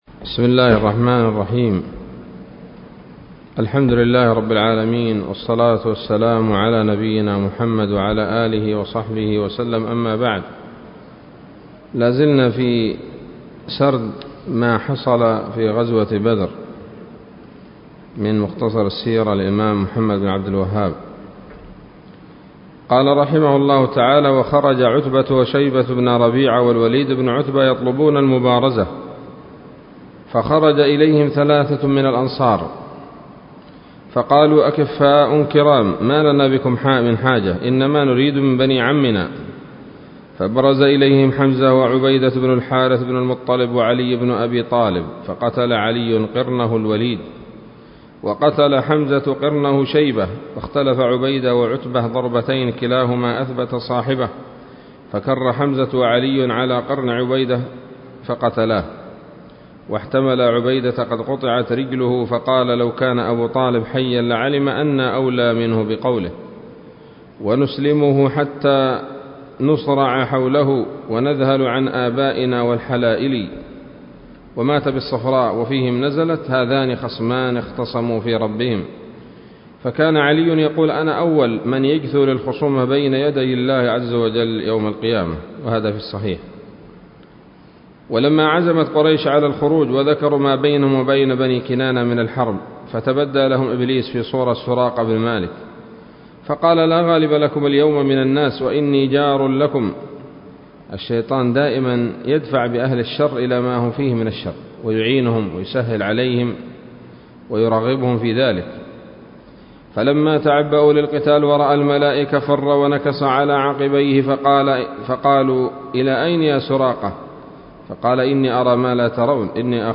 الدرس السابع والثلاثون من مختصر سيرة الرسول ﷺ